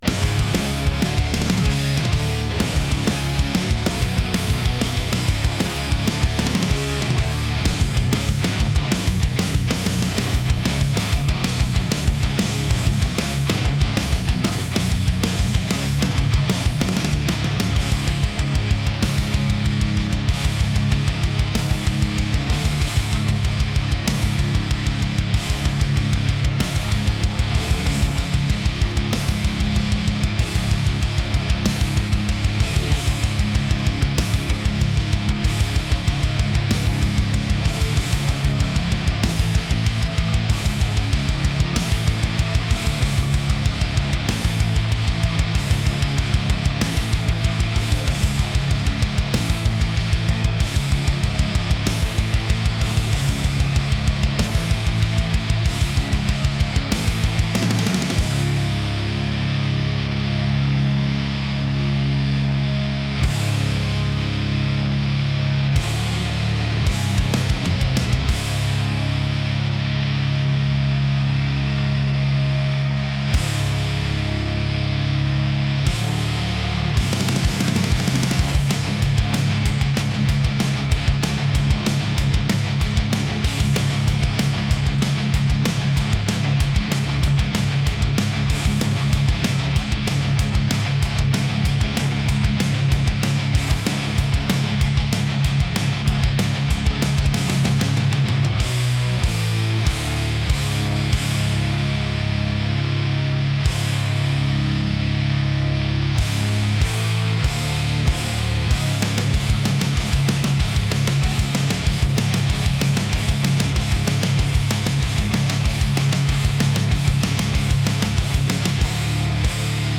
metal | aggressive | heavy